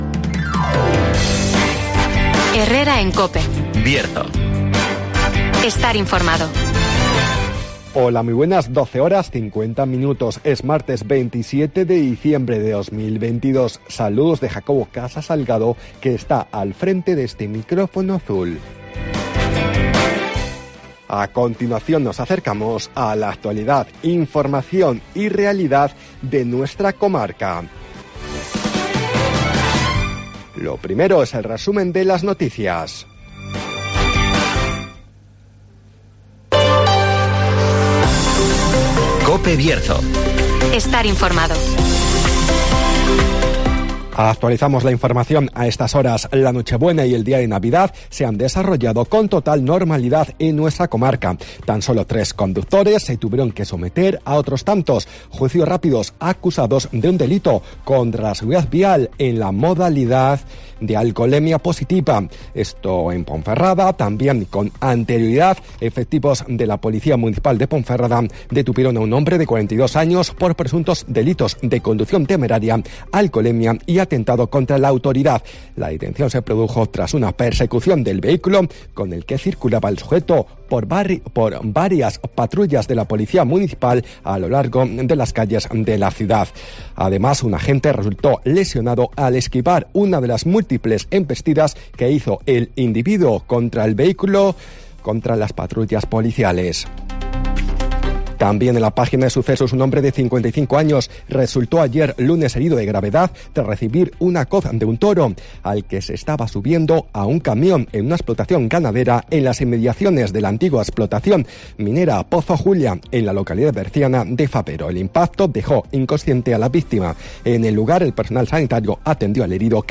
AUDIO: Resumen de las noticas, el Tiempo y la agenda